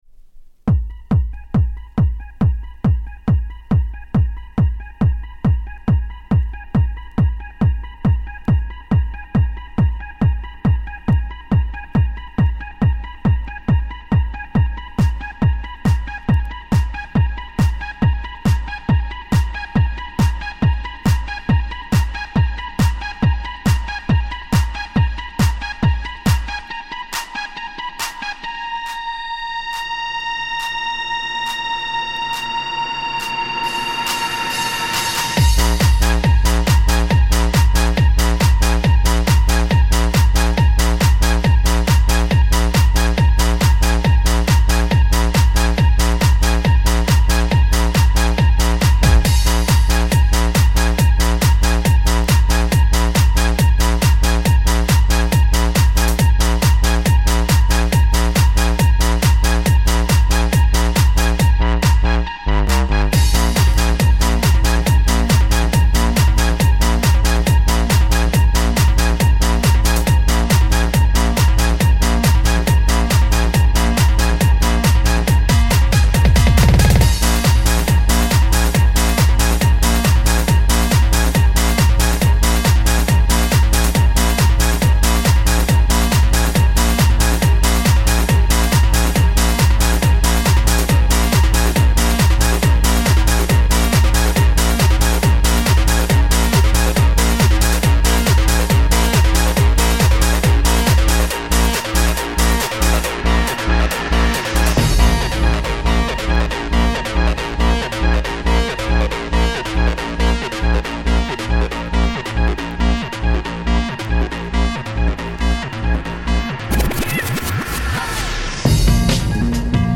Жанр: Club